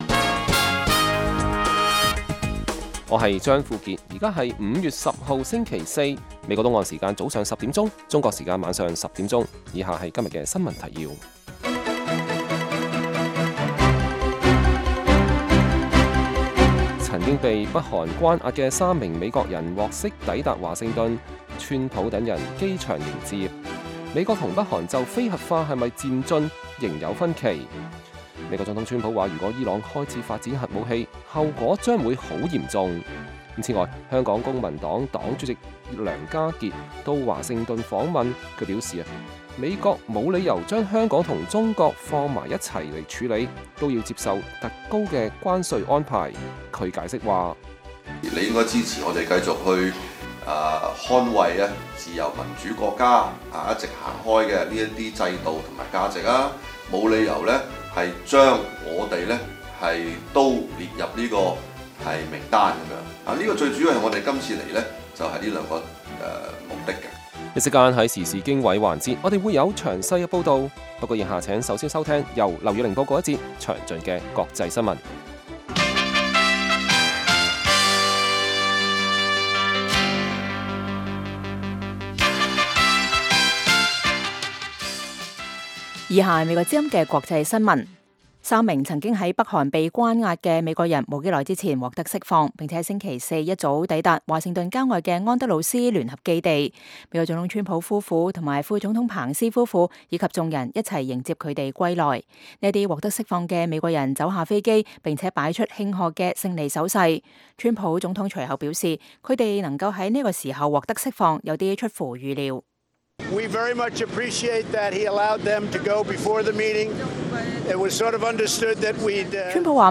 粵語新聞 晚上10-11點
北京時間每晚10－11點 (1400-1500 UTC)粵語廣播節目。內容包括國際新聞、時事經緯、英語教學和社論。